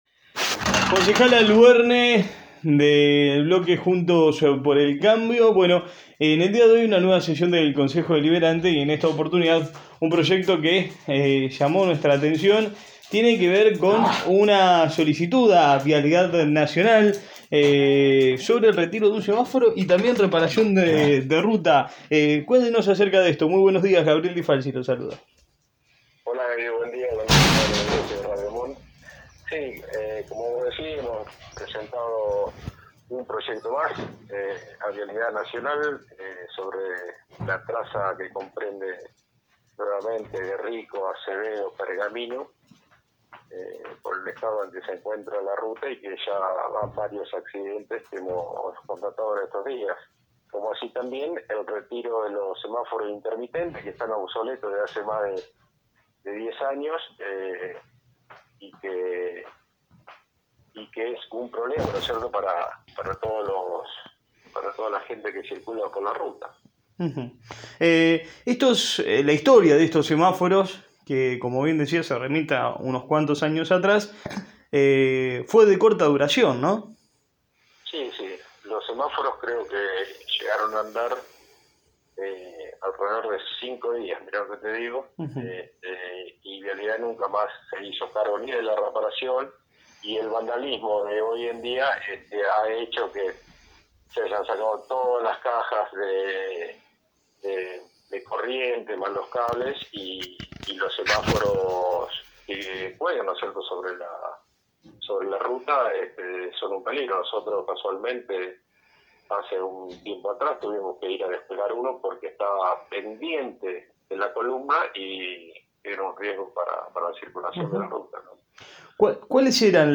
El concejal Guillermo Albuerne, dialogó durante la mañana de éste martes en la previa a una nueva sesión del HCD.